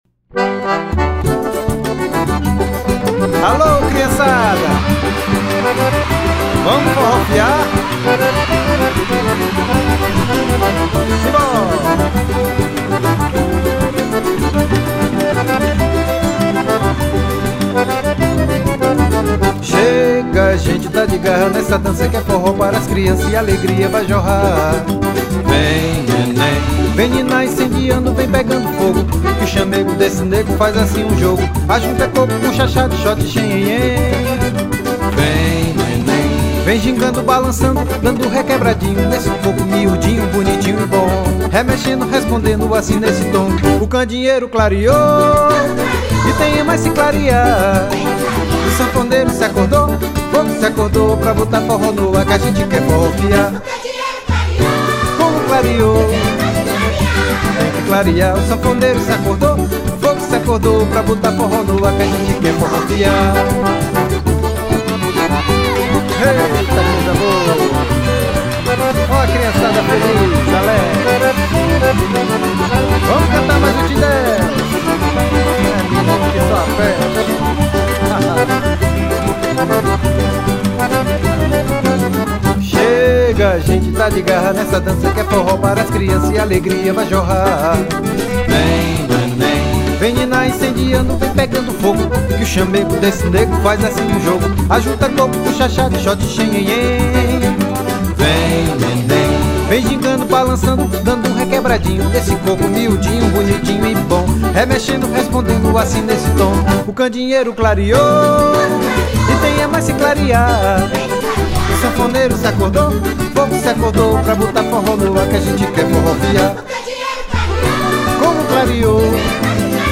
1442   03:16:00   Faixa:     Forró
Acoordeon, Voz
Violao 7
Guitarra
Viola de 12 cordas
Cavaquinho
Flauta
Bateria
Percussão